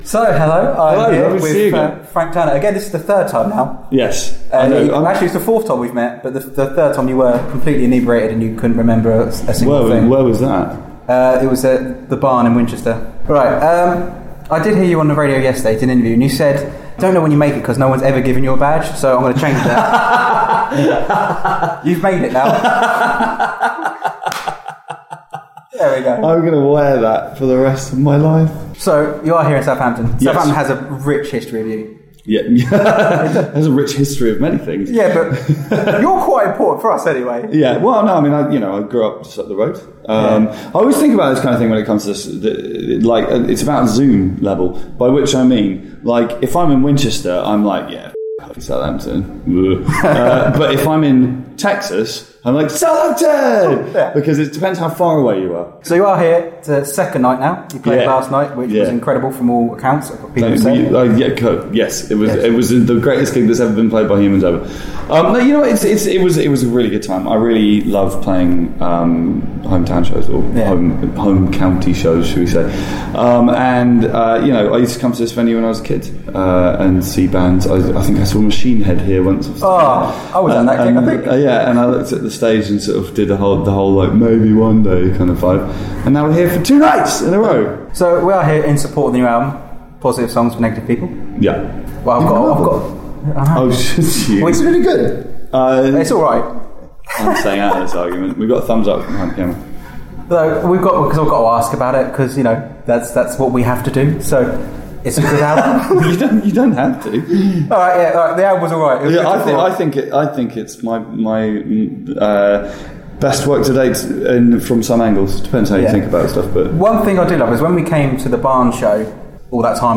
Frank Turner interview 07.11.15
I caught up with Frank Turner for the third time in Southampton at the 02 Guildhall and played another round of Frank on Frank! and we were joined by his buddy Timothy Omundson